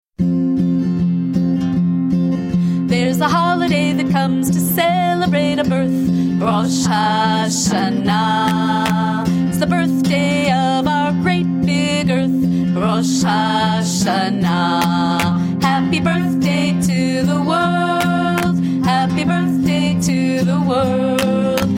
vocals and violin